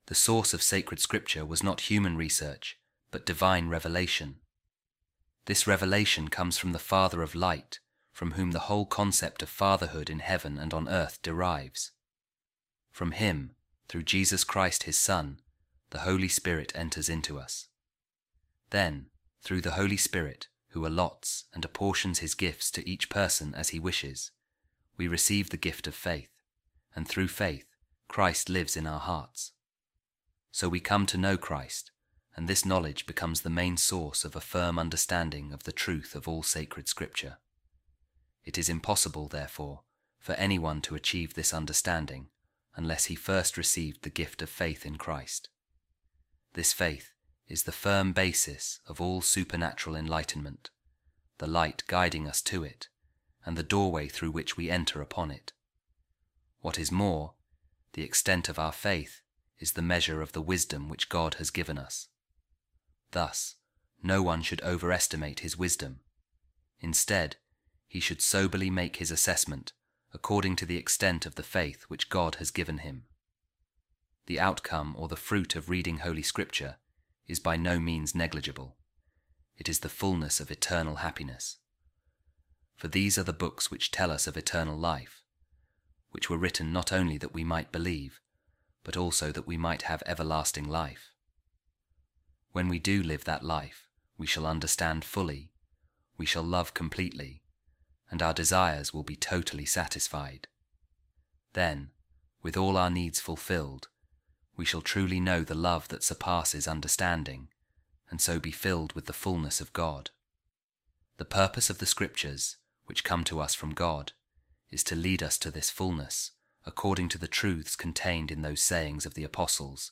Office Of Readings | Week 5, Monday, Ordinary Time | A Reading From The Breviloquium Of Saint Bonaventure | He Who Knows Jesus Christ Can Understand All Sacred Scripture